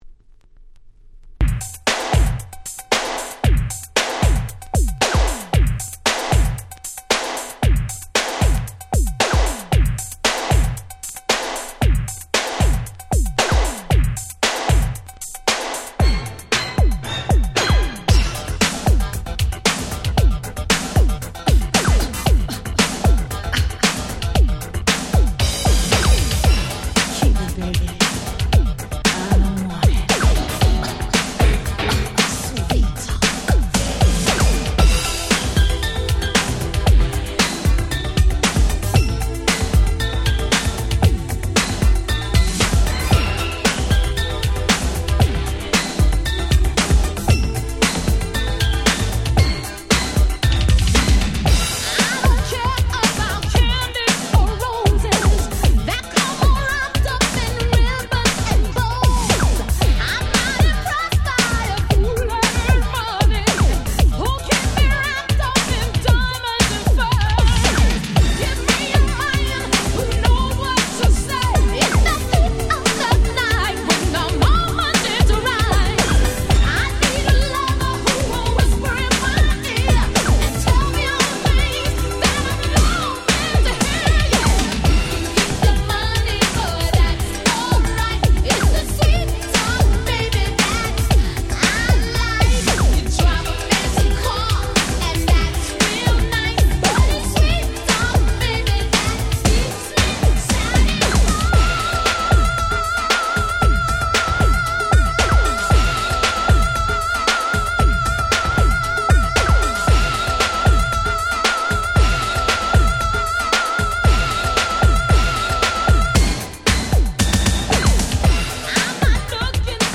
89' Smash Hit R&B / New Jack Swing !!
当時の空気感プンプンな「New Jack Swing前夜」的音使いな踊れる1曲！！
こちらのRemix盤はオリジナルよりもポコポコしたBeatで軽くて良い感じ！！
ニュージャックスウィング NJS ハネ系 ビルボードヒット 80's